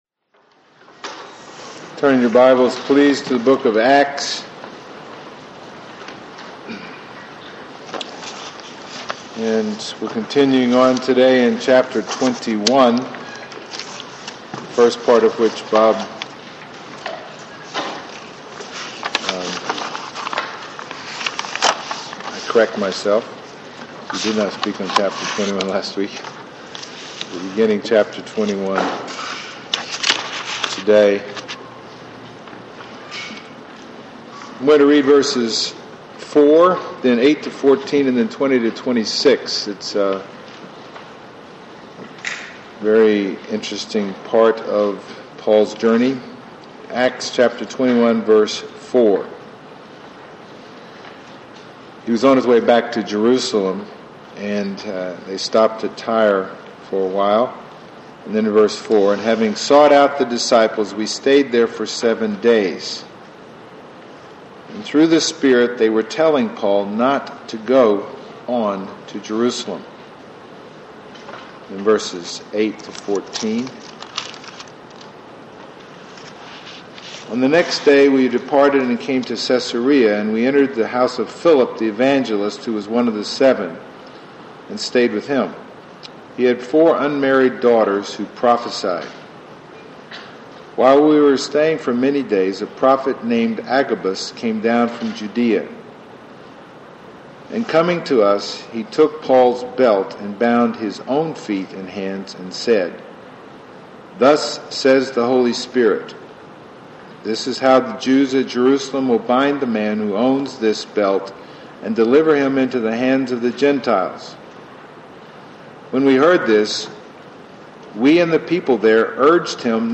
Passage: Acts 21:4-26 Service Type: Sunday Morning